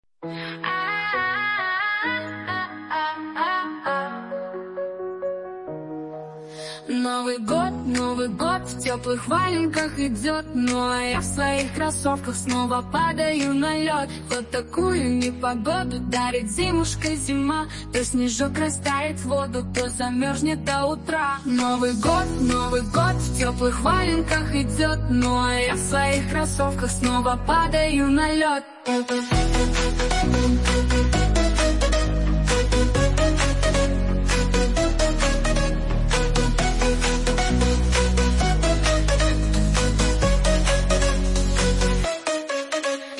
Фрагмент 1 варианта исполнения: